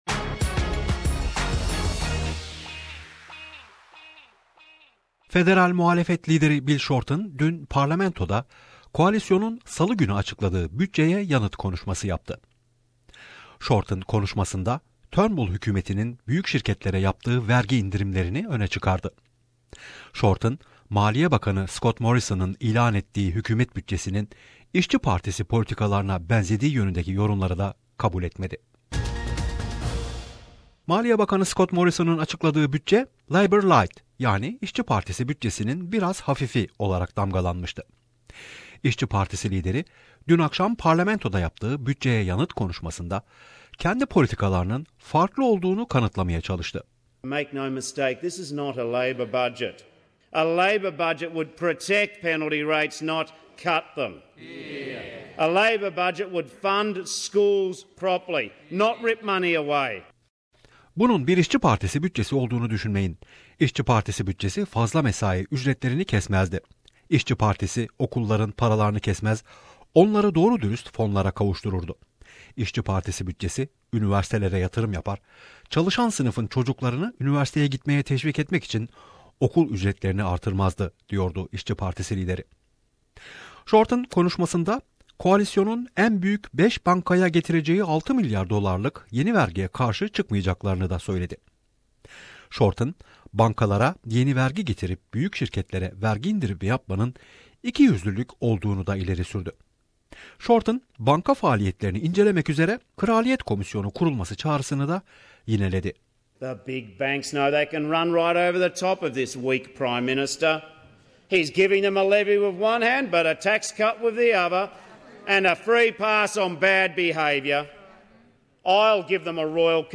Bill Shorten delivers Budget reply speech